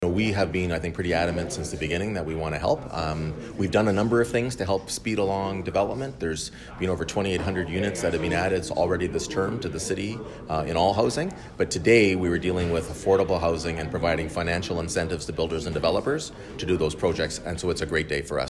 Mayor Mitch Panciuk says council knew there was a housing crisis from the beginning of its term and took concrete action to help solve it.